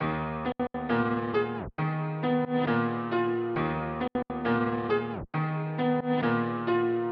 钢琴旋律 135 bpm D
Tag: 135 bpm Trap Loops Piano Loops 1.20 MB wav Key : D